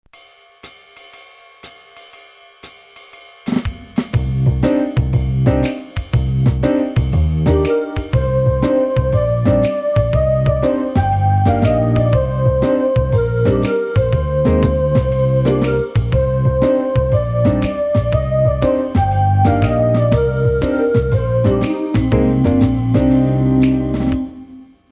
Swing piece